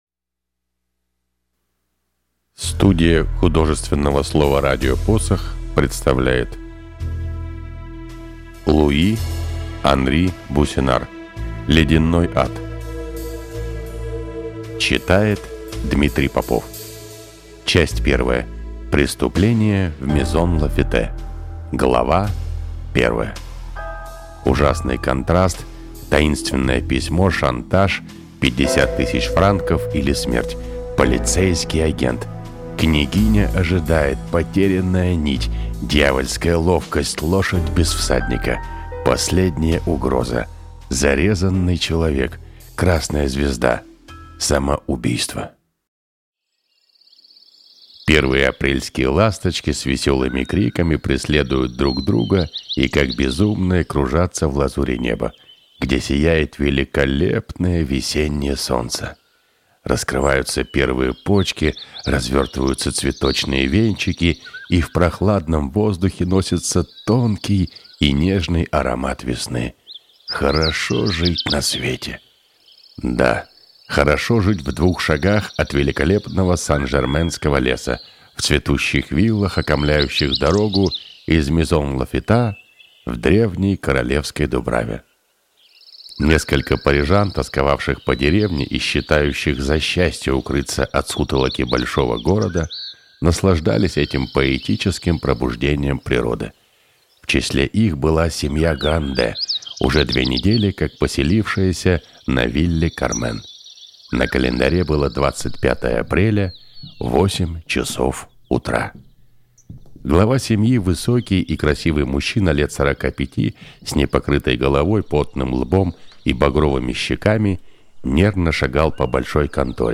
Аудиокнига Ледяной ад | Библиотека аудиокниг
Прослушать и бесплатно скачать фрагмент аудиокниги